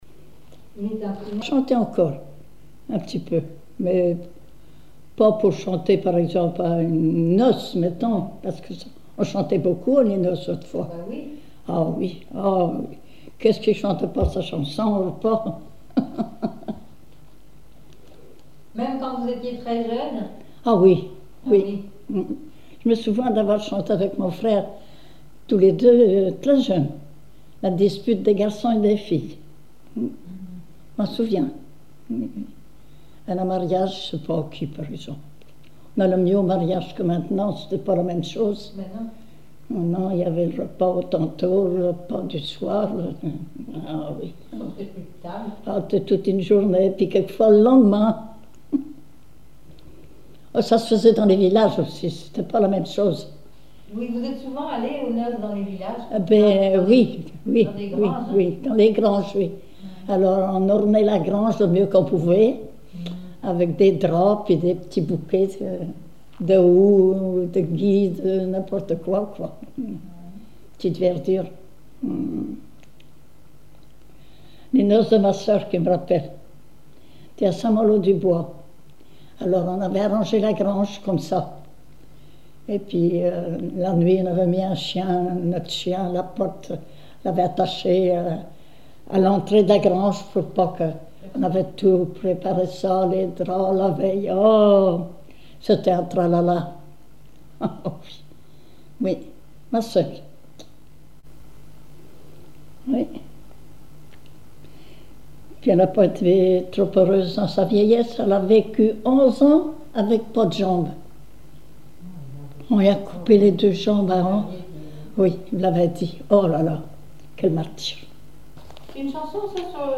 chanteur(s), chant, chanson, chansonnette
Catégorie Témoignage